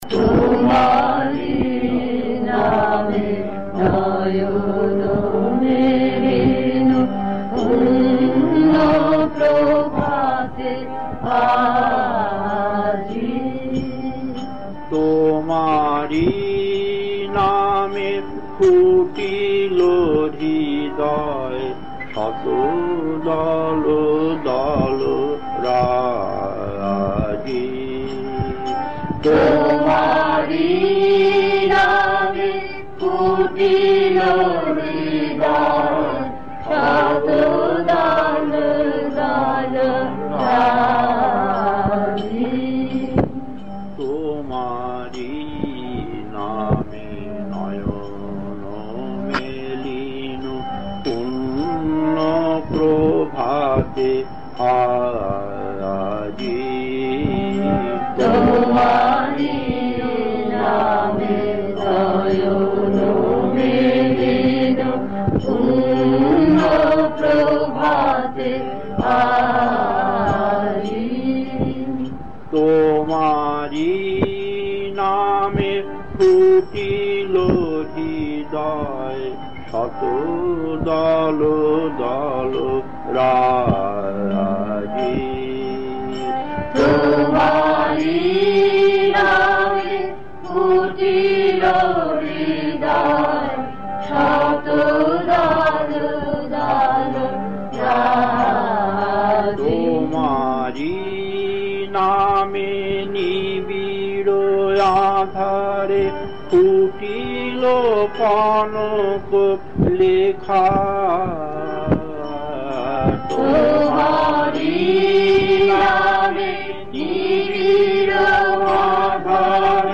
Kirtan C5-1 Puri, 1980, 36 minutes 1.